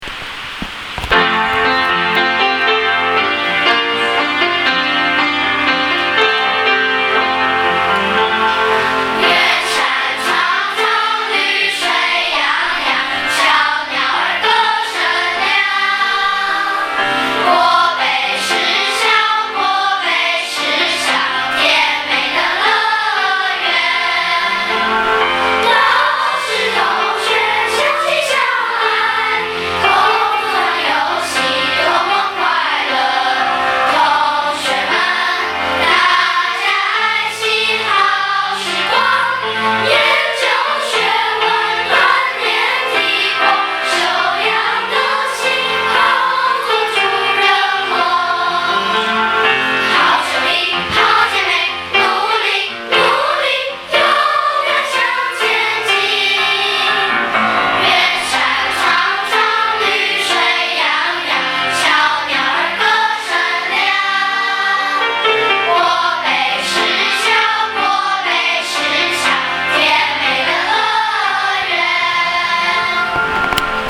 合唱團版
播放校歌合唱團版
school_song.mp3